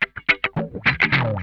CRUNCHSWOOP2.wav